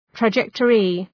{trə’dʒektərı}